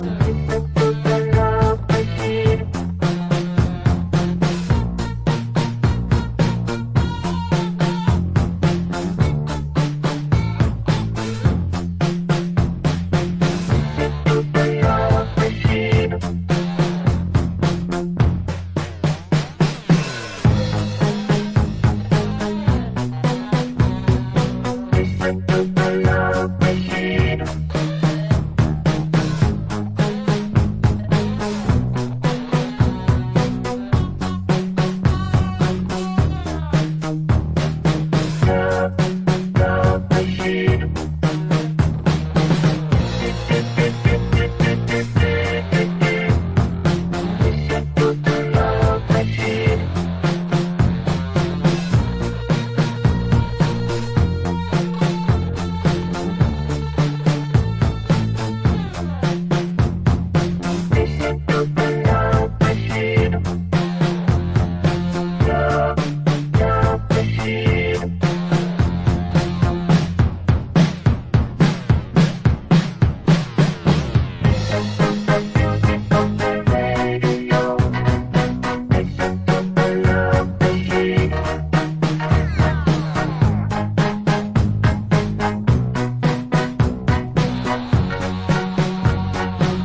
italo disco, cosmic disco, disco rock, proto house
Disco